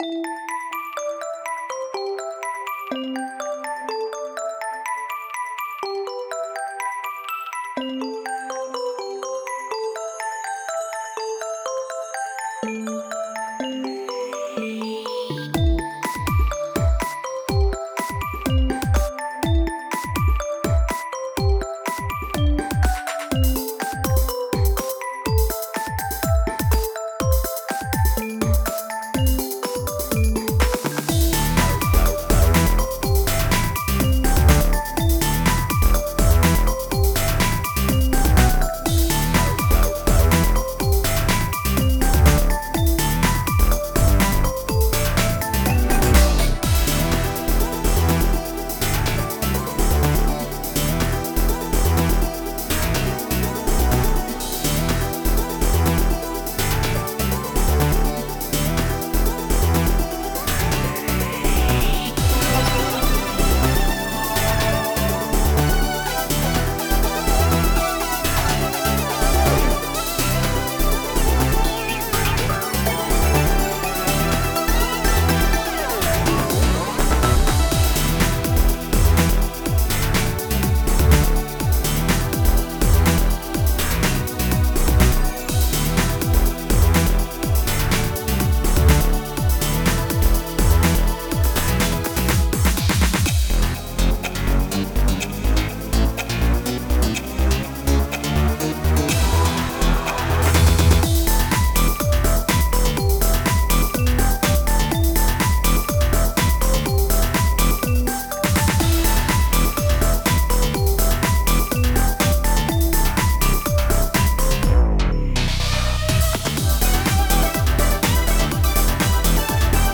Style: Synthpop / Dance
Another upbeat coop track